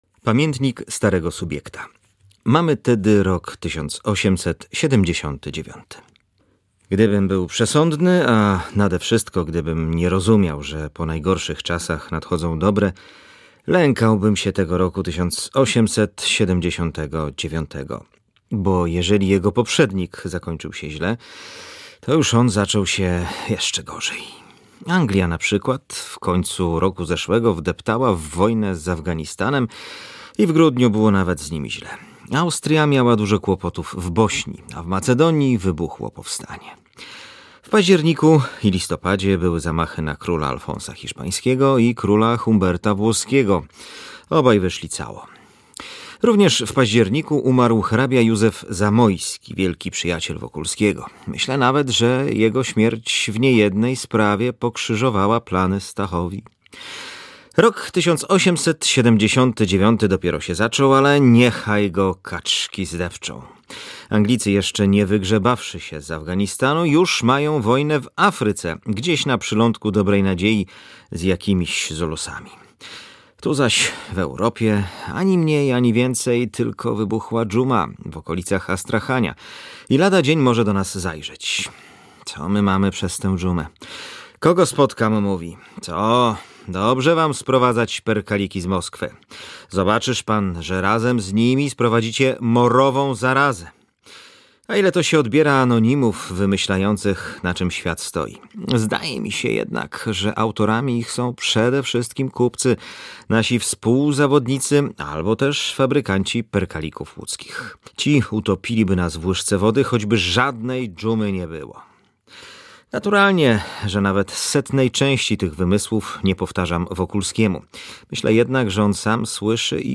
Przez całą sobotę, na naszej antenie, znani olsztynianie czytali fragmenty „Lalki”: